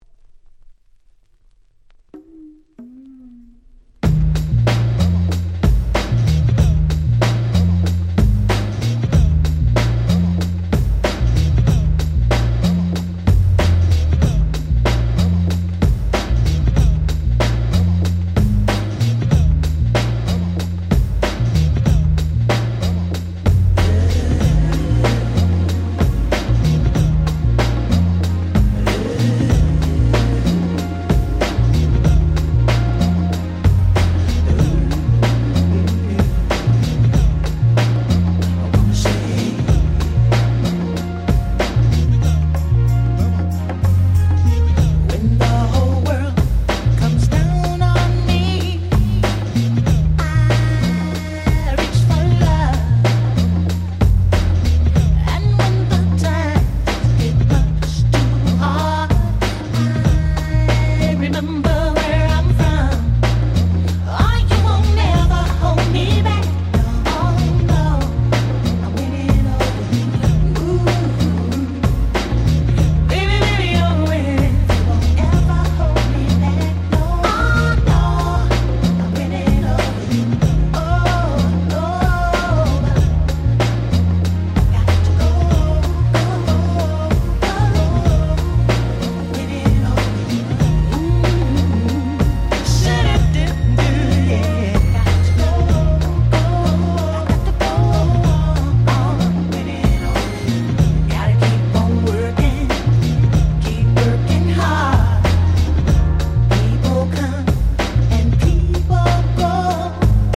94' Nice R&B/Hip Hop Soul !!